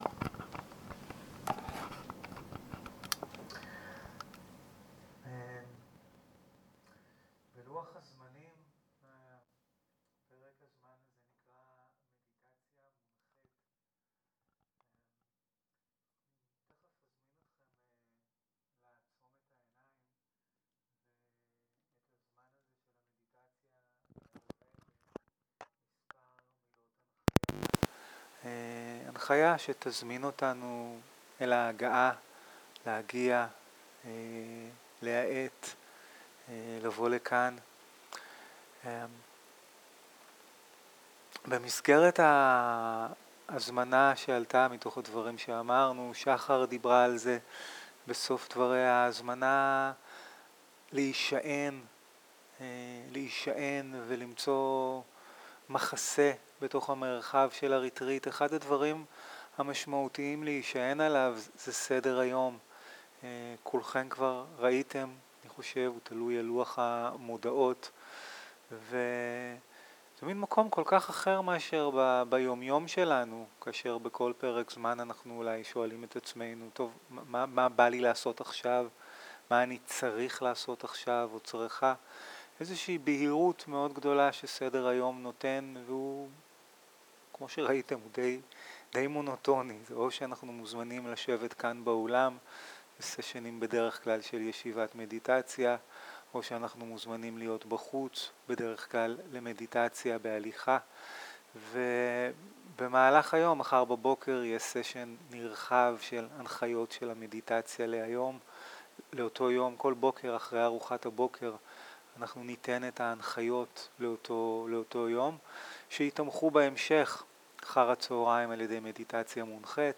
Dharma type: Guided meditation שפת ההקלטה